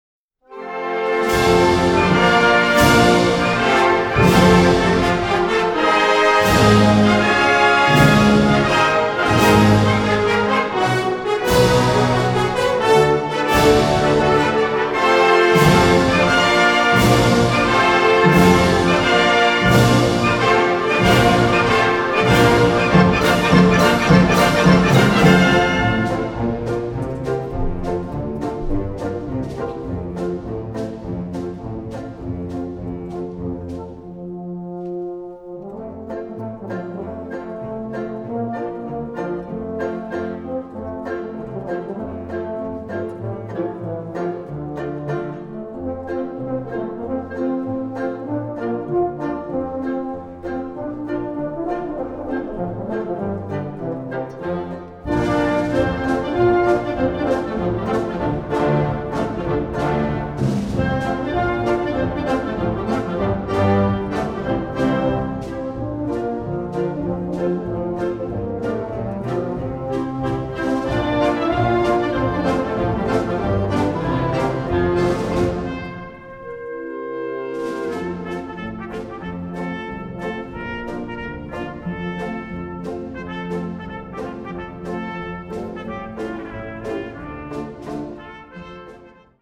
Kategorie Blasorchester/HaFaBra
Unterkategorie Suite
Besetzung Ha (Blasorchester)
Tänze und Gesänge